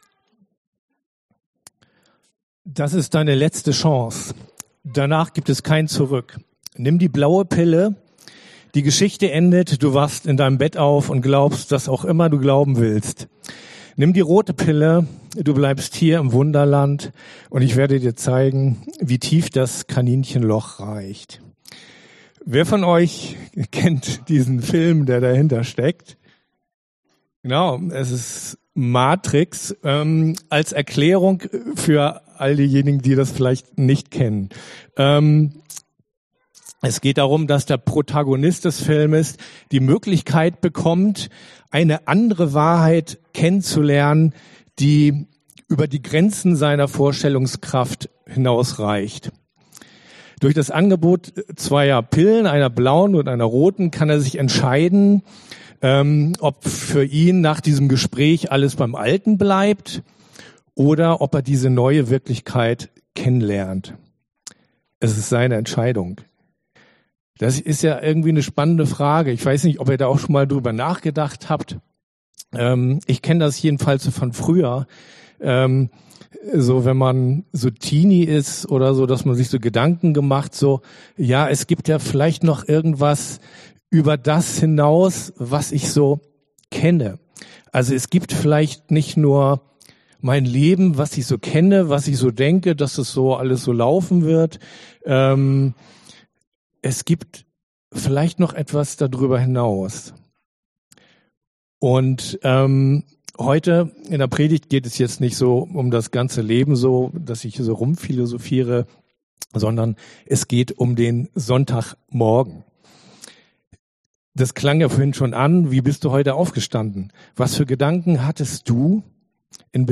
Lobpreis & Gebet Dienstart: Predigt Themen